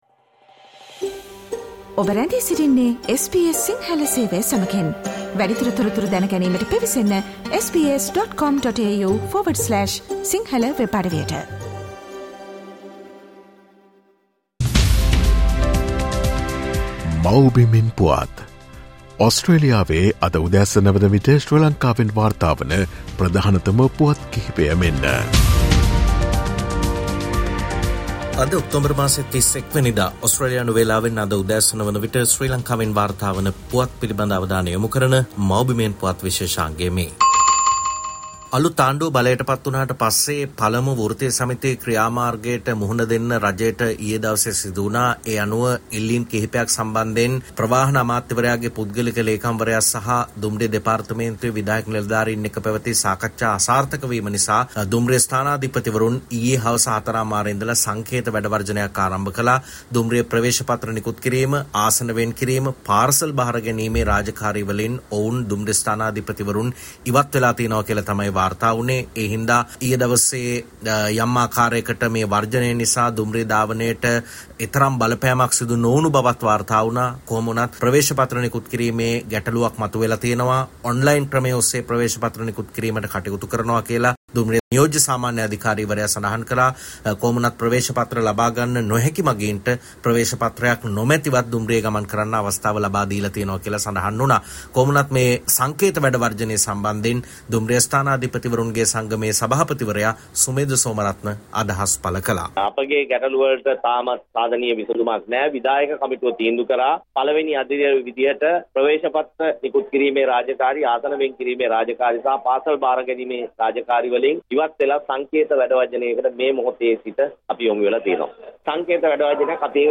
Supreme court dismisses petition against Ranjan Ramanayake's nomination: Homeland News 31 Oct | SBS Sinhala